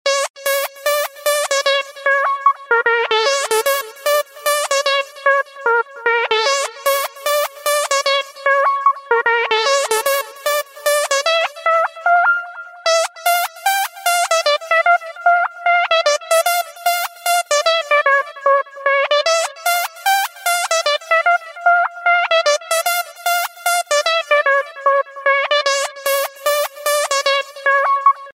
زنگ موبایل بامزه